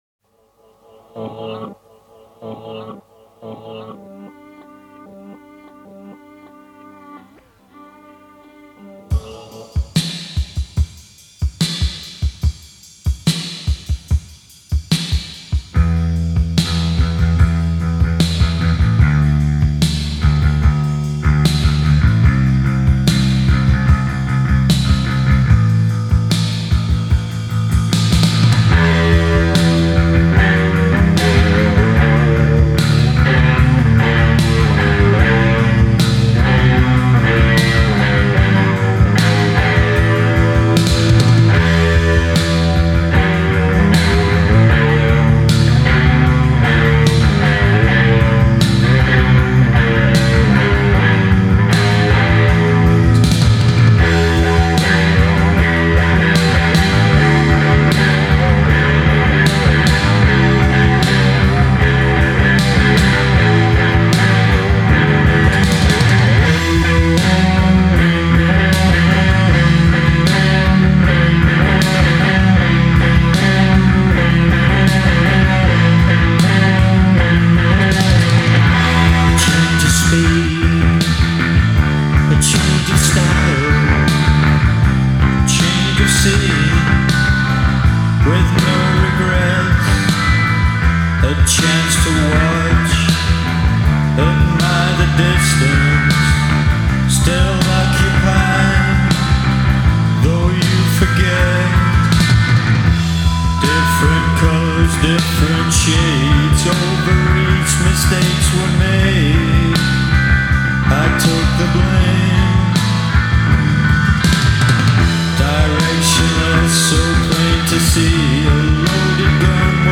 Пост панк